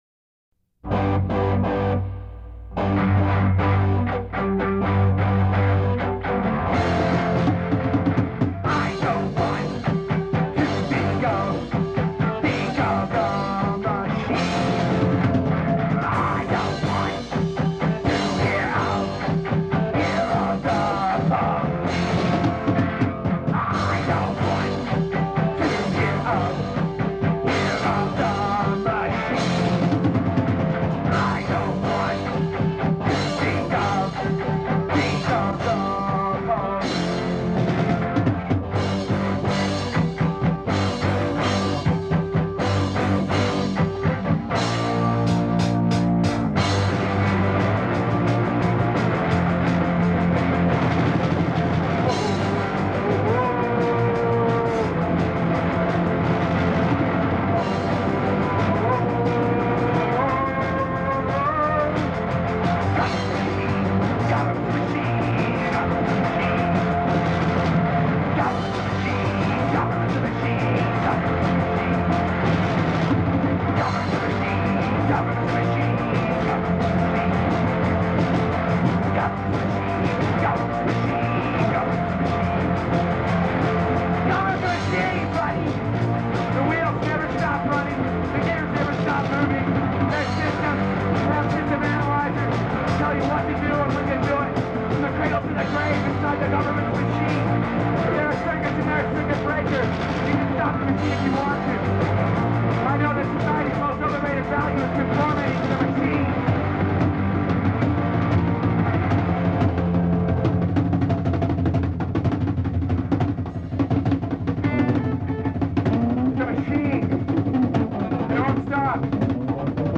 in the land of Tucson punk rock